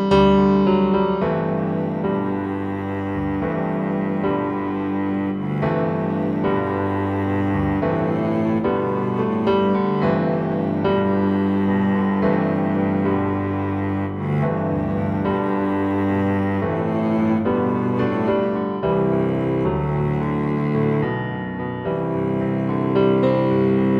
Down 5 Semitones For Male